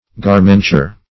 Garmenture \Gar"men*ture\, n. Clothing; dress.